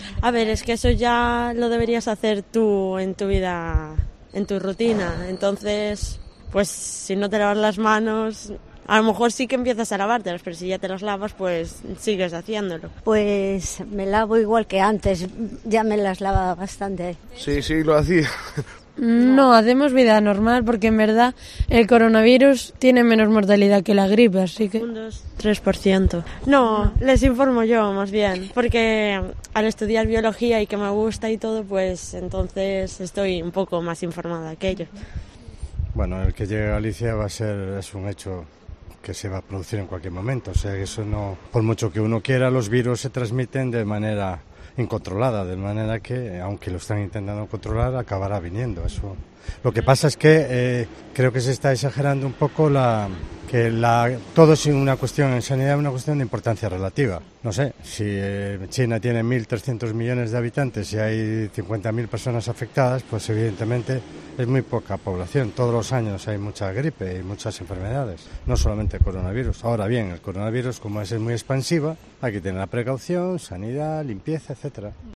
Los vecinos de Pontevedra hablan sobre su higiene y el coronavirus